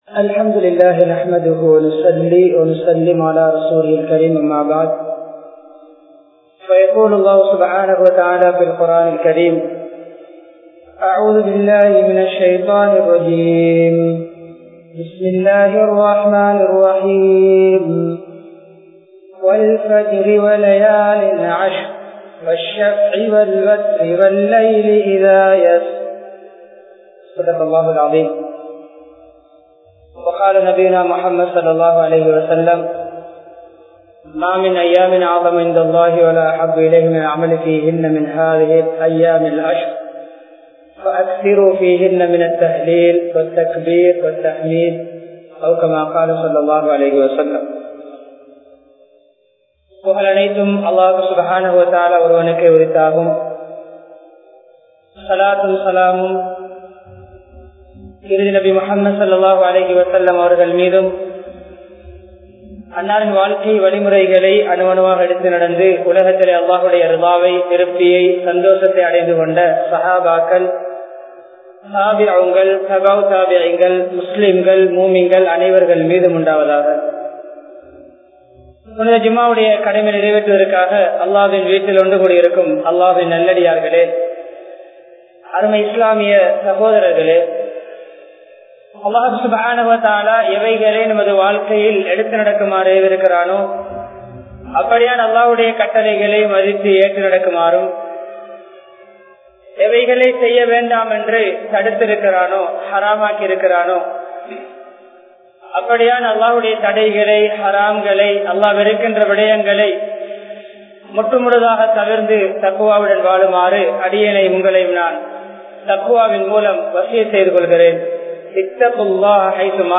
Dhul Hajj Maatha 10 Naatkal (துல்ஹஜ் மாத 10 நாட்கள்) | Audio Bayans | All Ceylon Muslim Youth Community | Addalaichenai
Colombo 12, Aluthkade, Muhiyadeen Jumua Masjidh